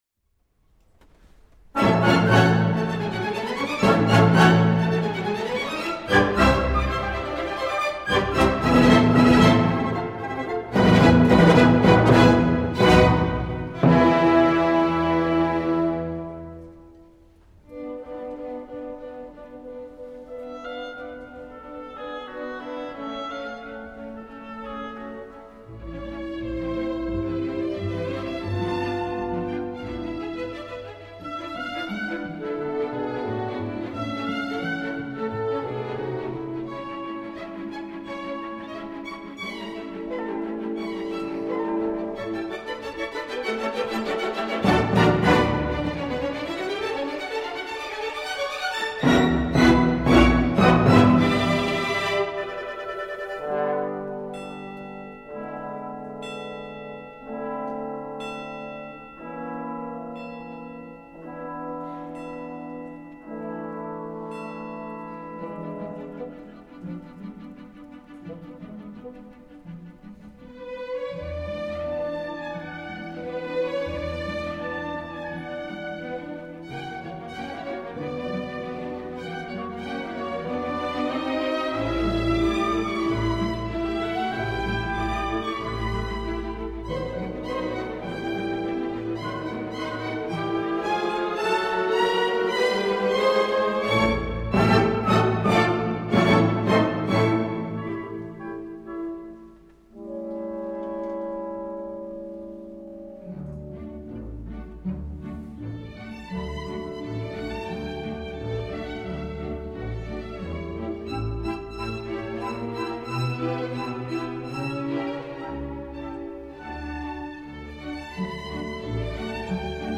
音乐对比鲜明，情感丰富，带有强烈的浪漫主义色彩。